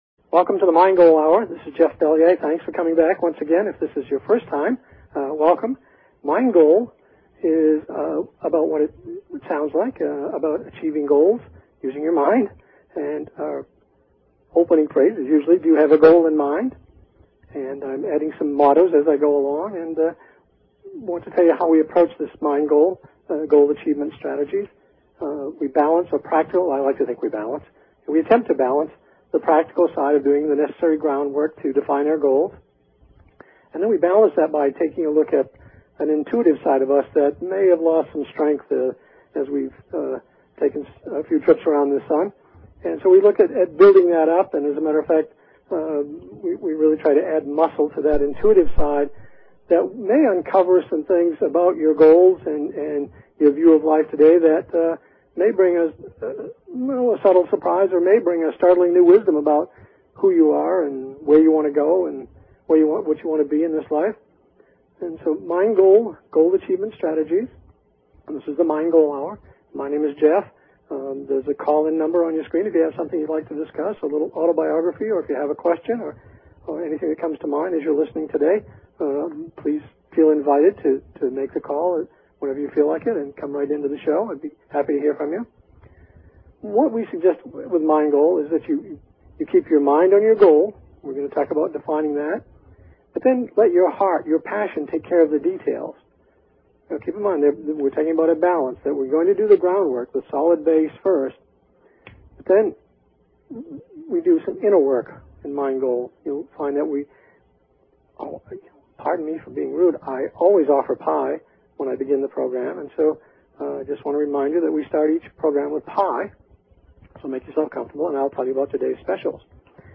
Talk Show Episode, Audio Podcast, Mind_Goal and Courtesy of BBS Radio on , show guests , about , categorized as